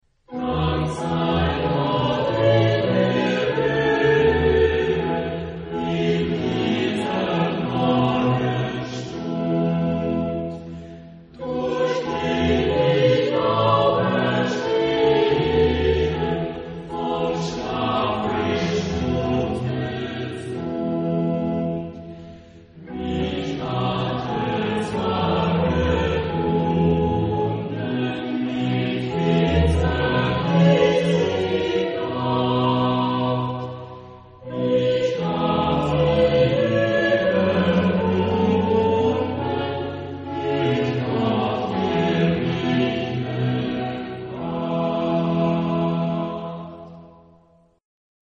Genre-Stil-Form: geistlich
Chorgattung: SATB  (4 gemischter Chor Stimmen )